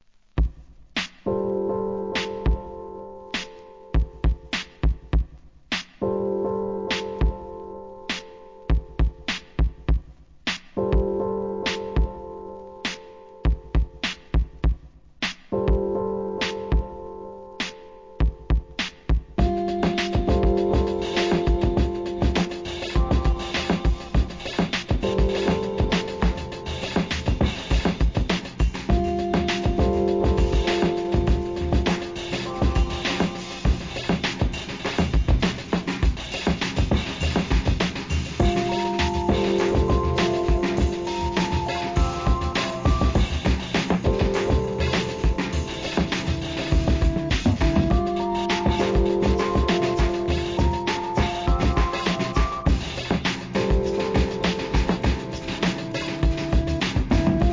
HIP HOP/R&B
仏ブレイクビーツ!!